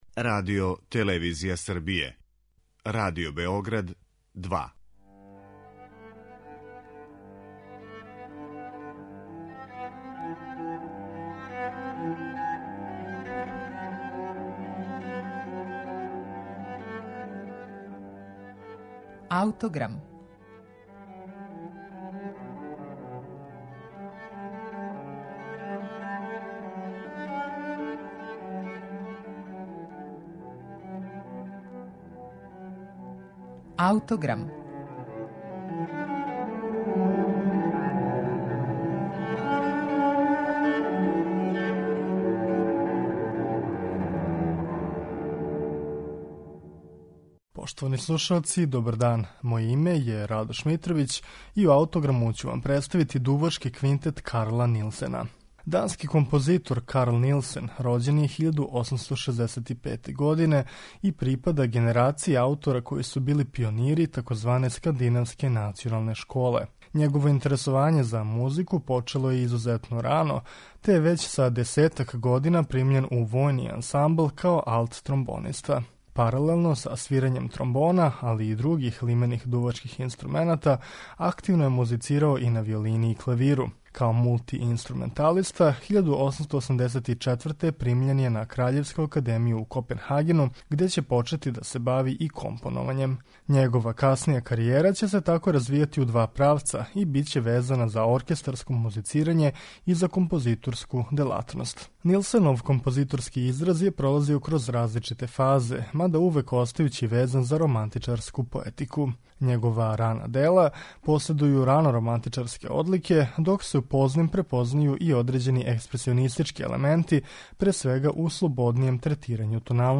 Слушаћете Дувачки квинтет Карла Нилсена
Дувачки квинтет Карла Нилсена слушаћете у извођењу ансамбла Скандинавски камерни извођачи.